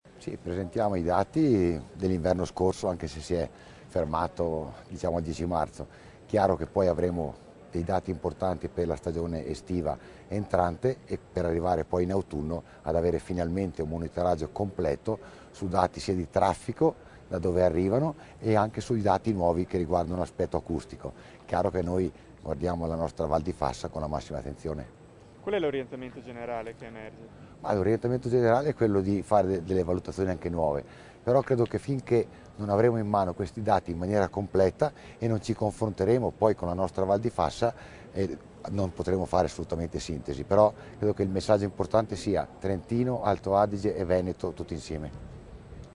Oggi a Selva di Val Gardena la conferenza stampa a cui ha partecipato l'assessore Failoni assieme al collega altoatesino Alfreider
INTV_ROBERTO_FAILONI_1+2_IT.mp3